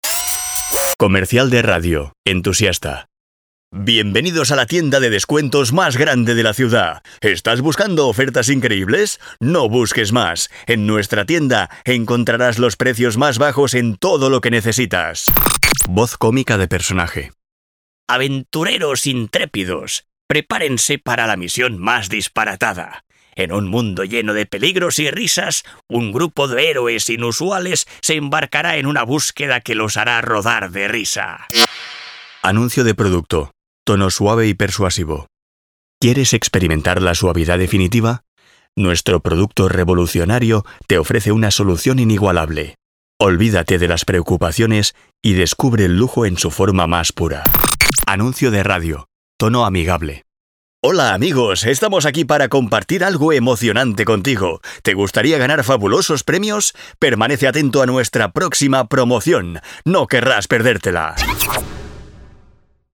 Male
Teenager (13-17), Adult (30-50)
I've a versatile voice. Can I do dynamic teenagers voices or voices elegants for corporates products. All records with the profesional quality of my studio.
Main Demo